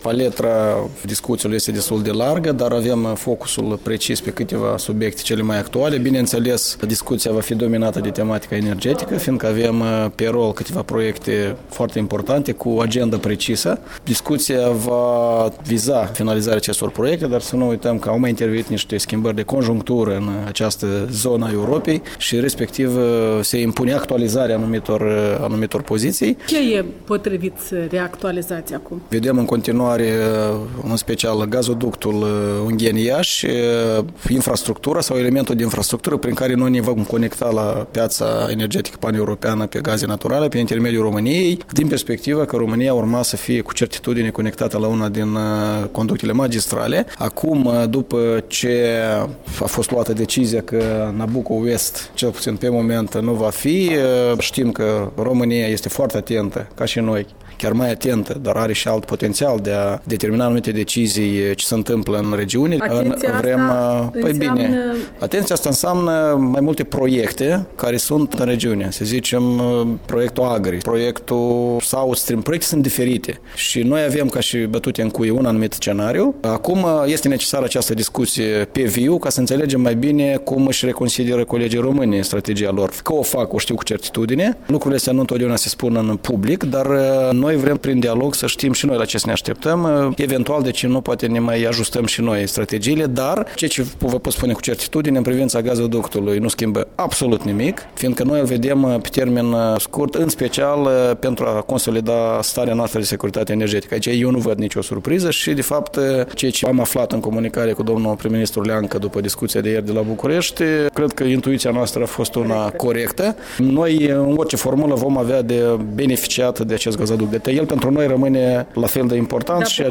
Interviu cu vicepremierul Valeriu Lazăr